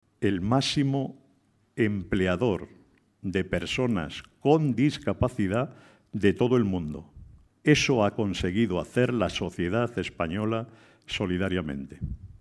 En una conferencia organizada por Fórum Europa pasa revista a la actualidad social y reclama "respeto y reconocimiento" para las organizaciones de la sociedad civil